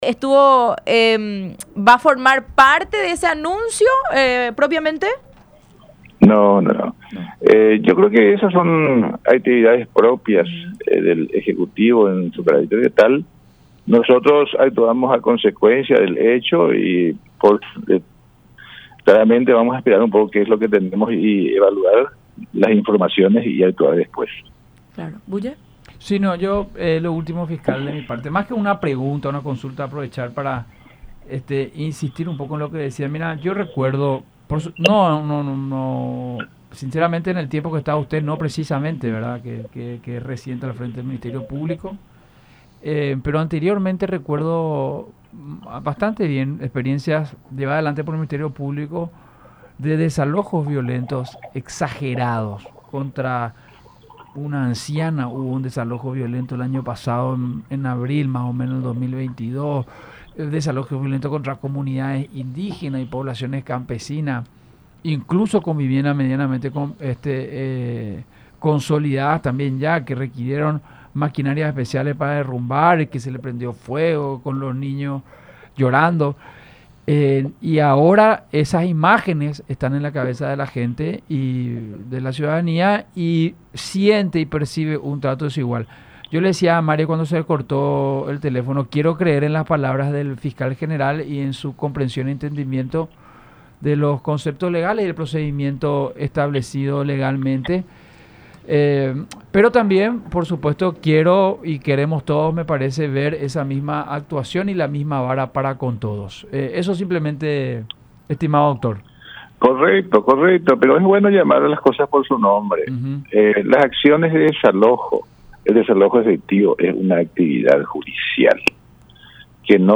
“No creo que se deba llevar el debate a ese extremo. No debemos dar connotación de que se trate de una cuestión de clases sociales o intereses económicos. La realidad es que el apoderamiento de un inmueble ajeno, tiene dos mecanismos de atención, la vía inmediata y el poder discrecional”, agregó en el programa “La Unión Hace la Fuerza por radio La Unión y Unión Tv.